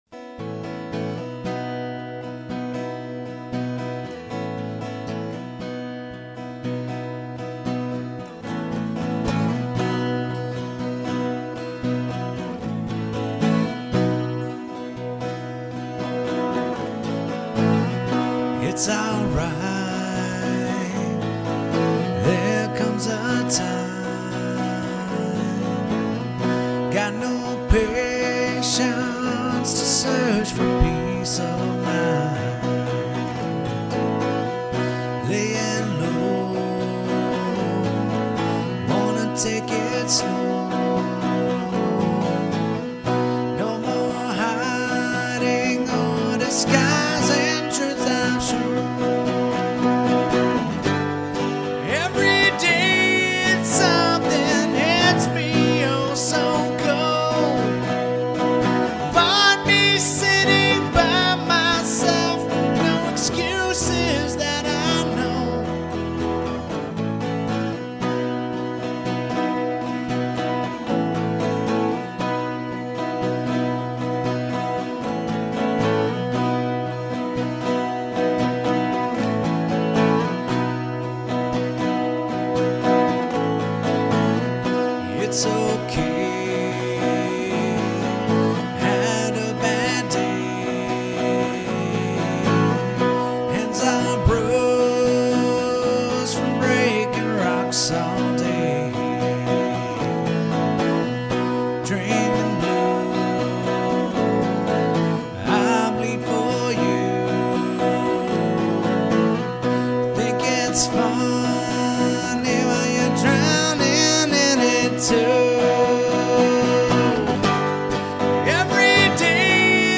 Rhythm Guitar I
Lead Guitar
Vocals